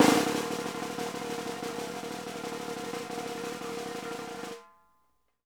PRESSROLL -L.wav